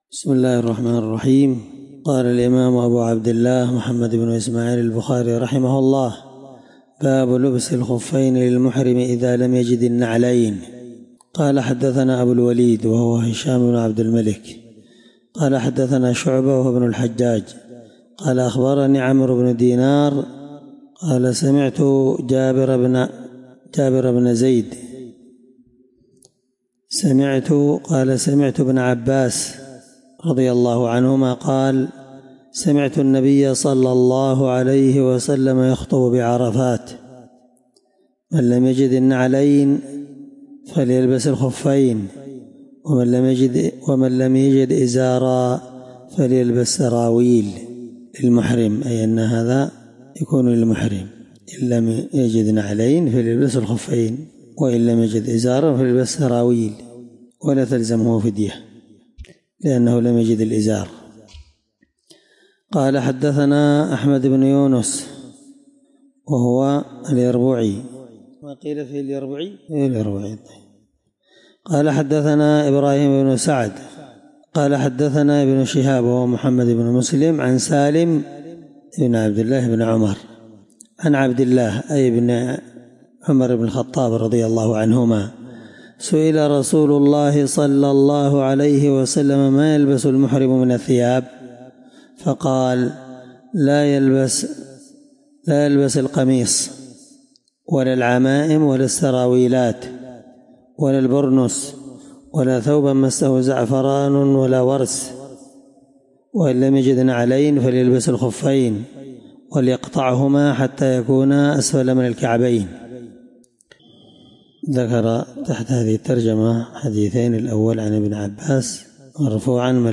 الدرس 15من شرح كتاب جزاء الصيد حديث رقم(1841-1842 )من صحيح البخاري